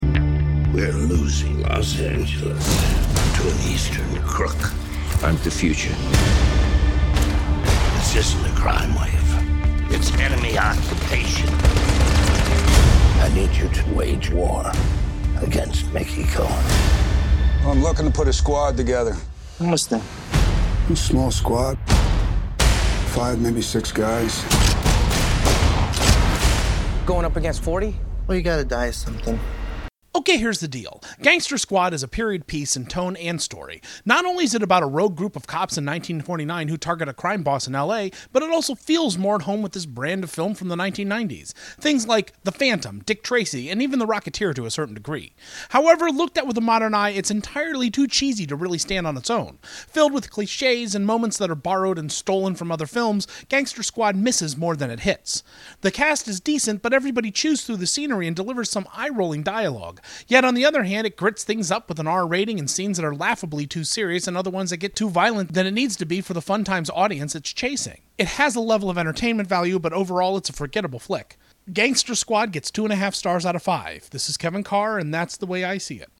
Movie Review: ‘Gangster Squad’